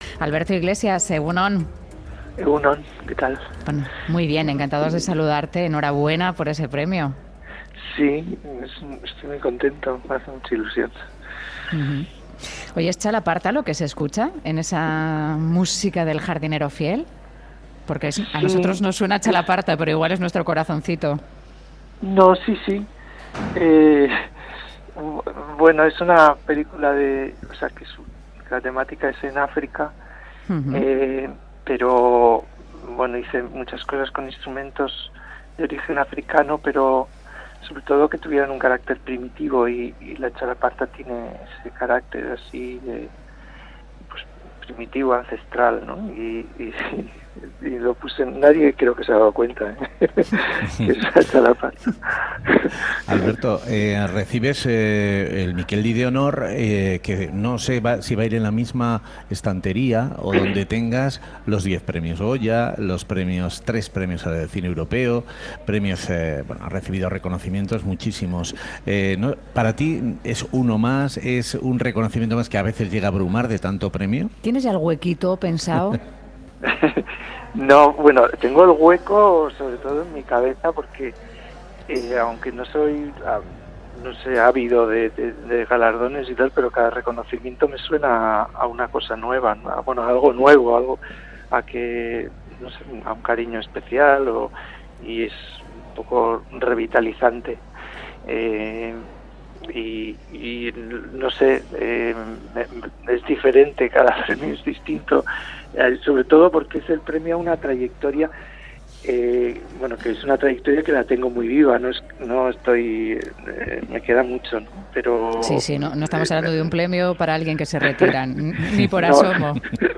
Audio: En 'Boulevard' han entrevistado a Alberto Iglesias, compositor donostiarra, que recibirá el 'Mikeldi' de honor dentro del Festival Zinebi de Bilbao.